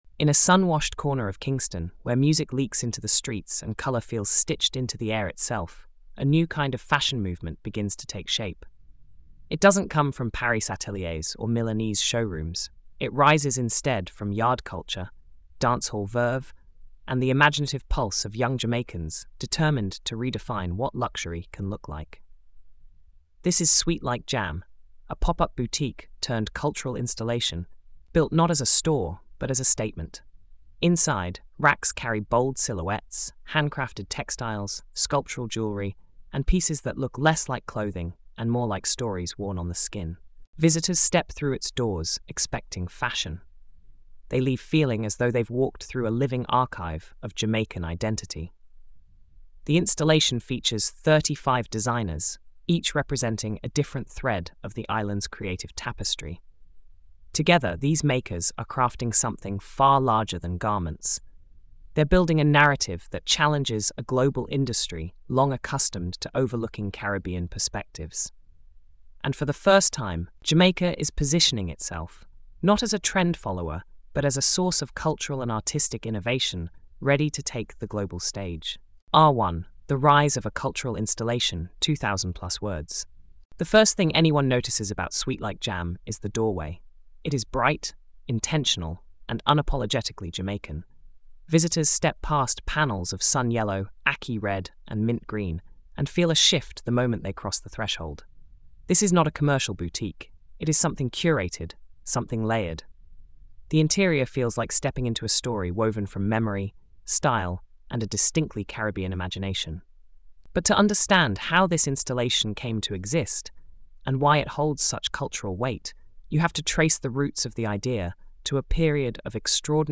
Fashioning Identity is a cultural-history documentary that explores how Jamaica’s groundbreaking installation, Sweet Like JAM, transformed from a Kingston pop-up into a global showcase of Caribbean innovation. Featuring thirty-five designers, the film reveals how each maker draws from heritage, craft traditions, and lived experience to redefine what luxury can look like in a Jamaican context.